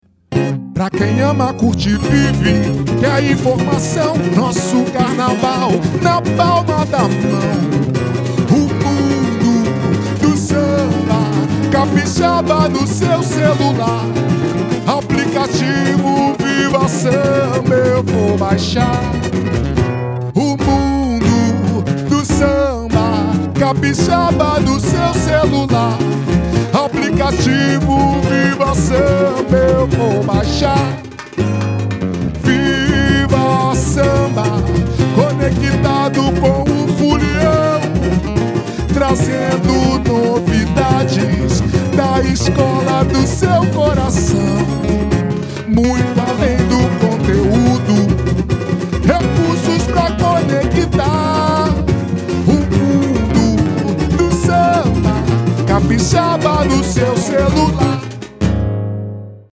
percussão geral
violão